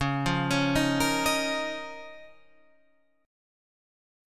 C#6add9 chord